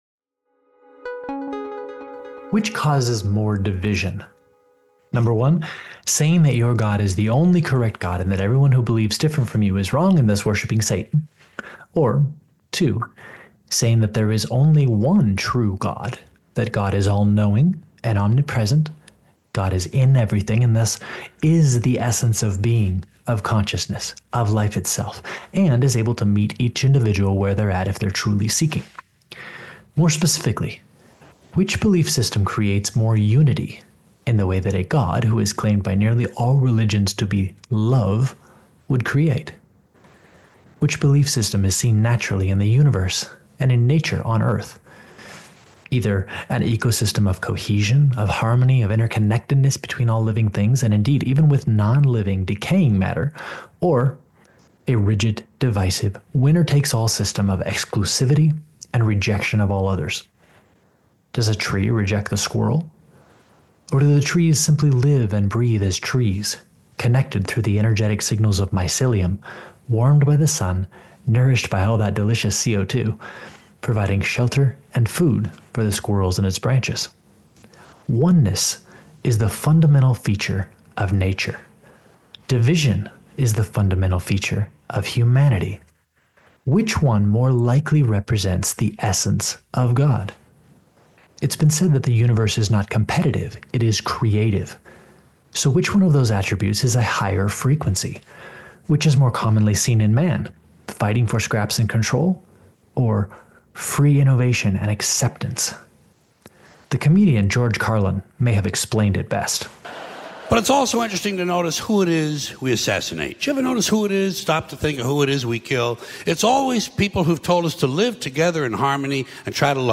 interviews religious and philosophical leaders from around the globe